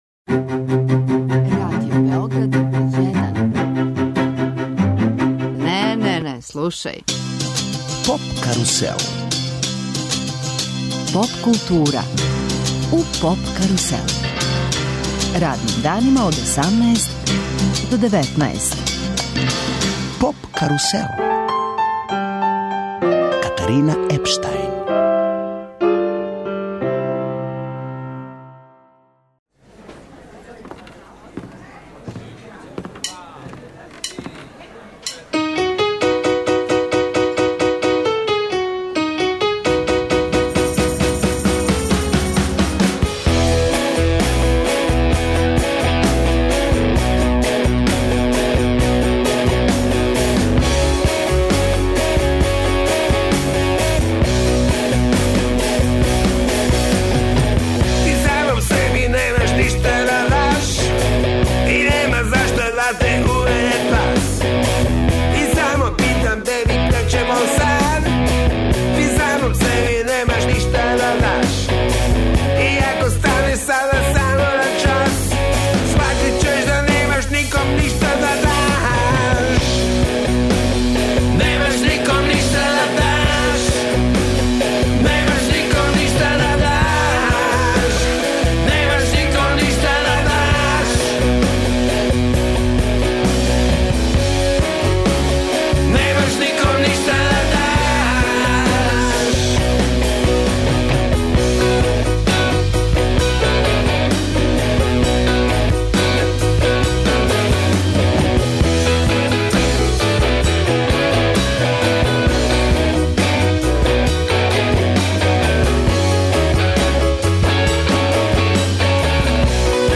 Тим поводом, наш гост је Срђан Гојковић.